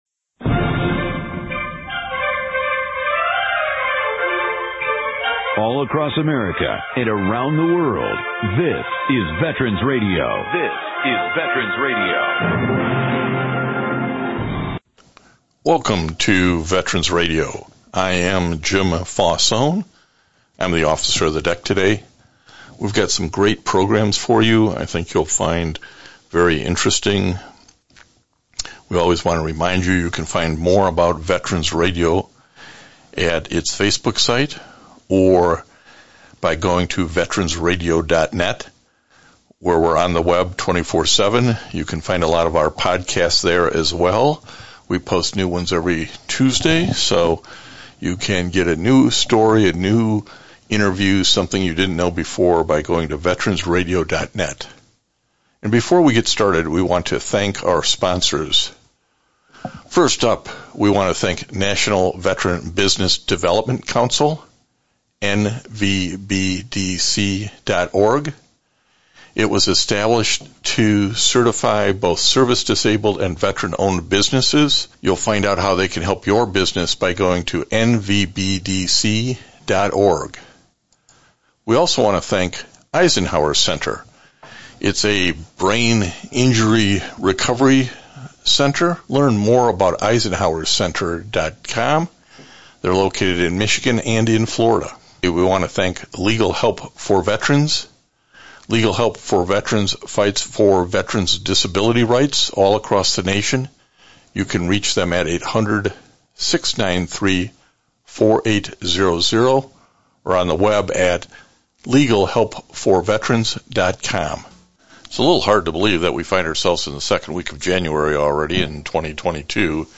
one hour radio broadcast